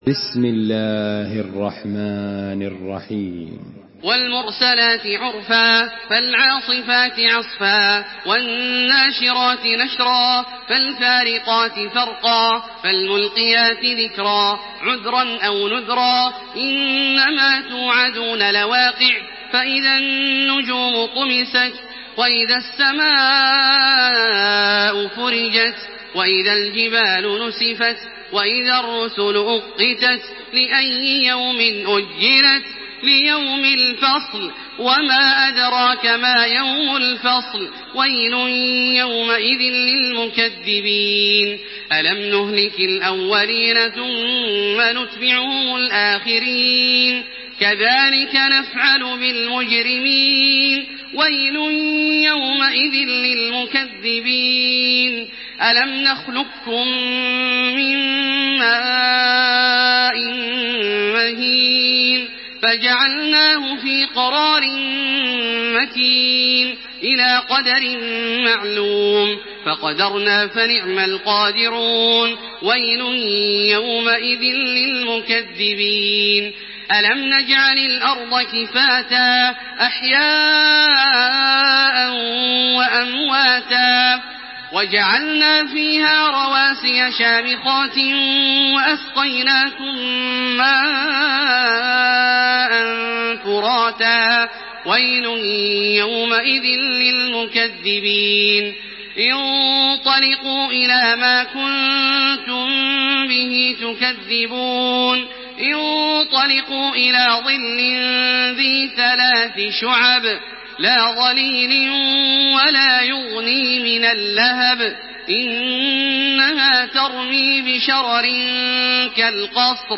Surah Al-Mursalat MP3 by Makkah Taraweeh 1428 in Hafs An Asim narration.
Murattal